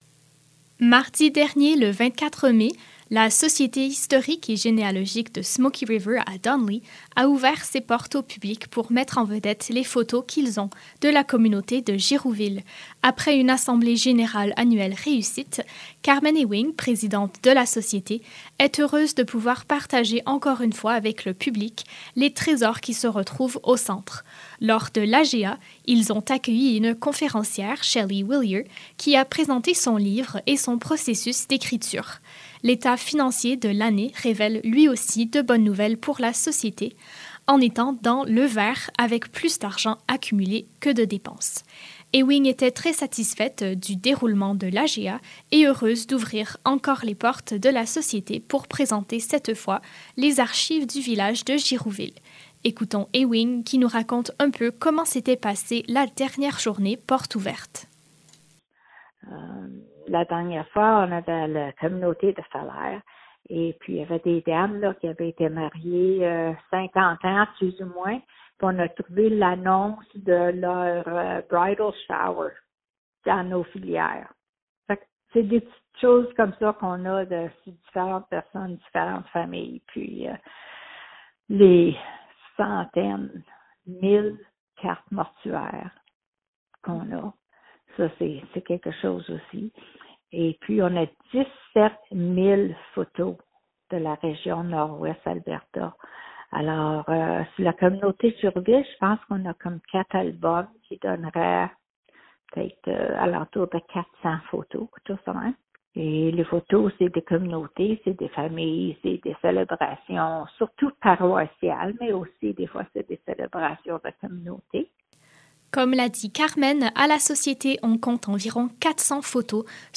Pour en savoir davantage sur la journée portes ouvertes, écoutez le reportage :